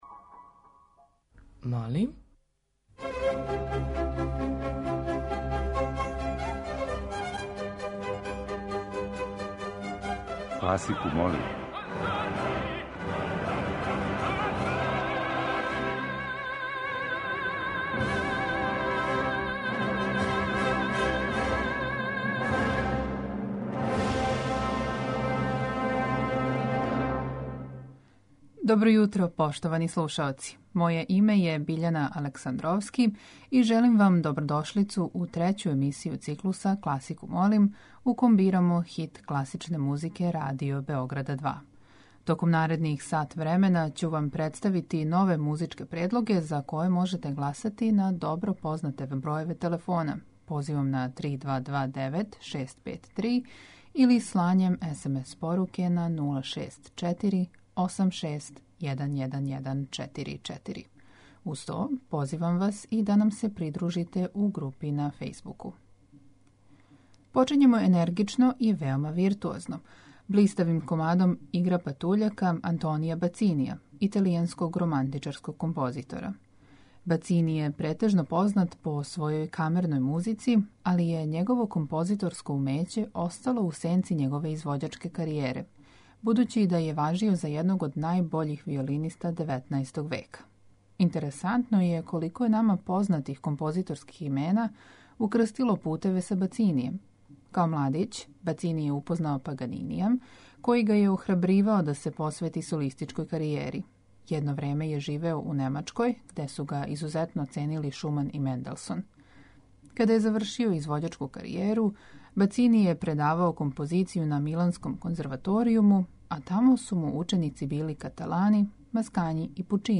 Класику, молим, уживо вођена емисија, разноврсног садржаја, окренута је широком кругу љубитеља музике, а подједнако су заступљени сви музички стилови, епохе и жанрови.